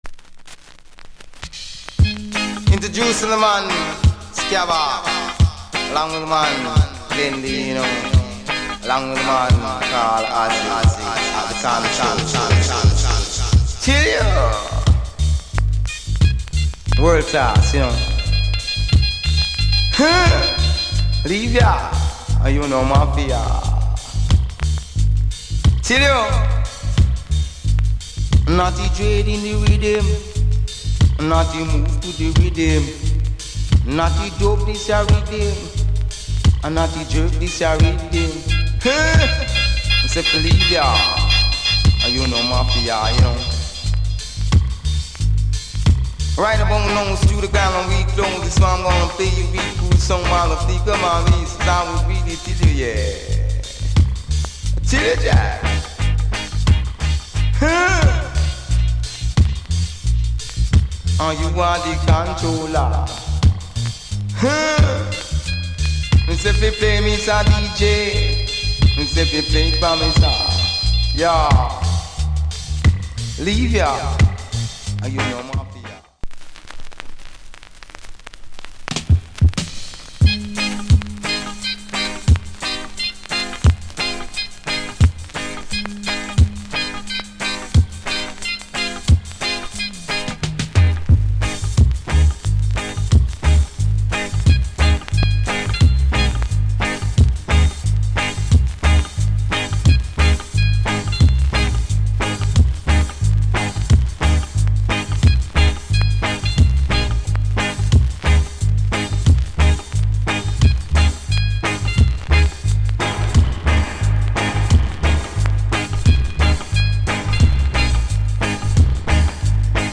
終盤にヒスノイズあり。